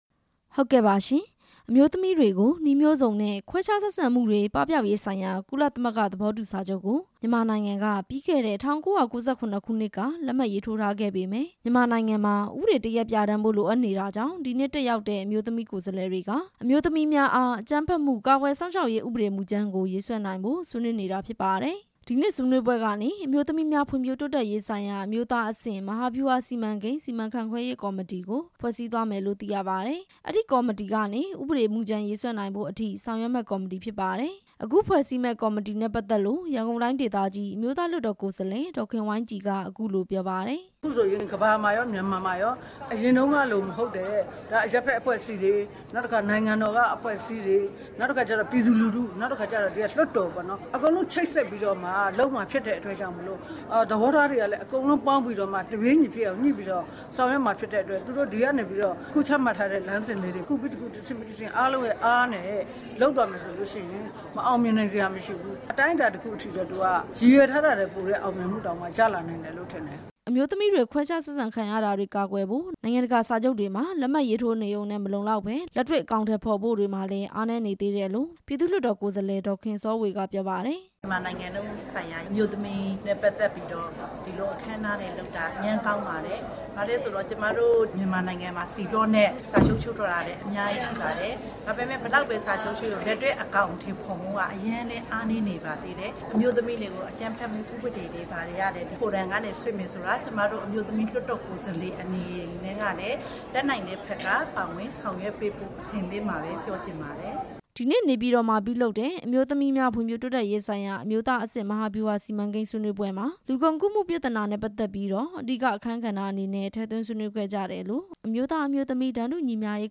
ဆွေးနွေးပွဲအကြောင်း တင်ပြချက်